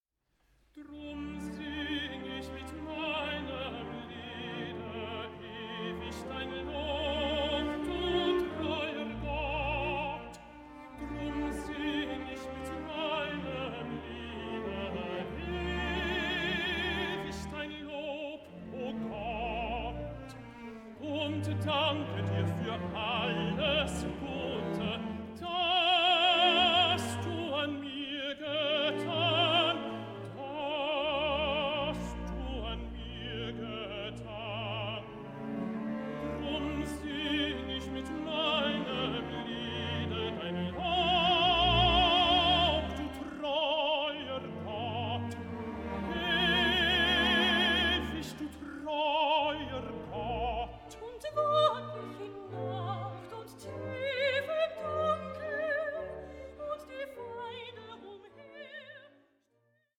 followed by a cantata with three soloists and chorus.